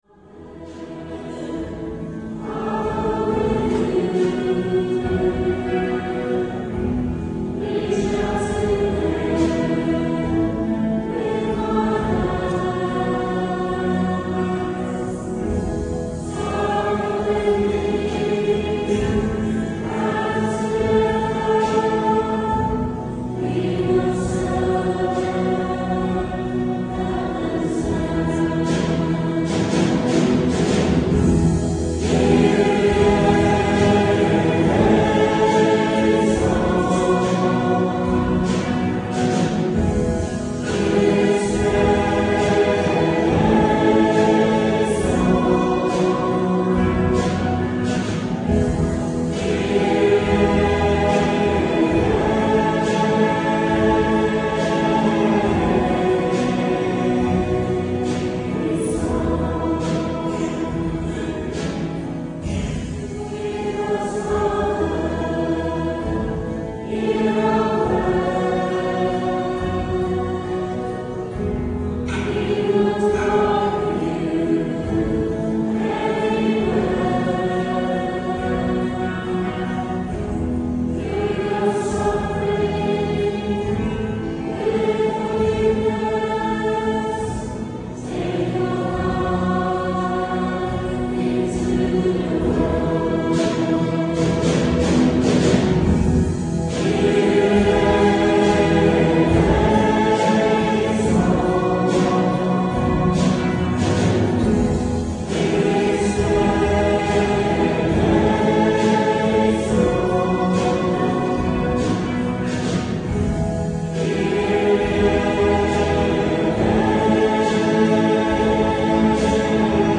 Recorded (in digital stereo on minidisc) at 10am Mass, 14th March 1999 with St.Thomas' congregation in good voice.
kyrie.mp3